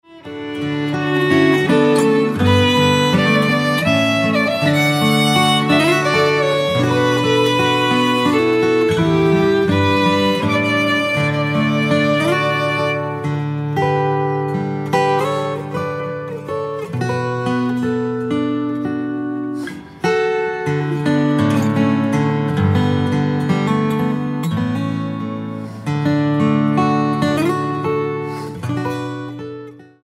Traditional hymn instrumentals for guitar, violin and flute